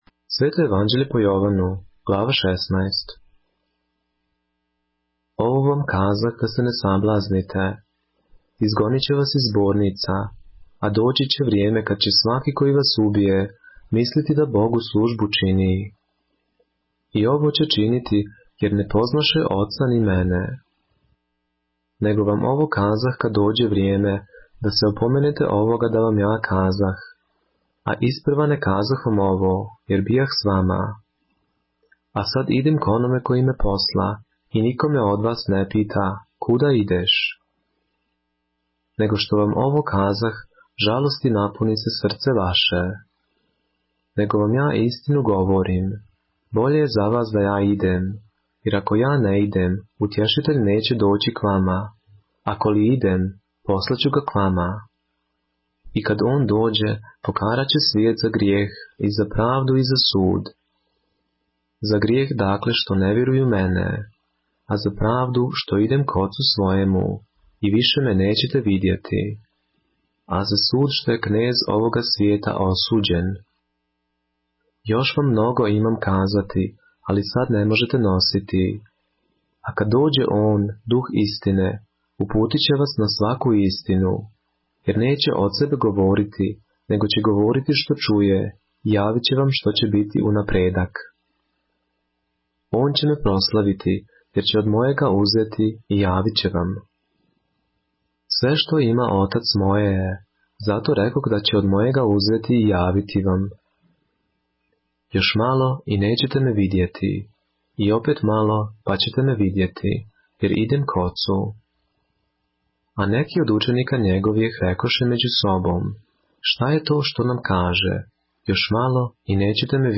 поглавље српске Библије - са аудио нарације - John, chapter 16 of the Holy Bible in the Serbian language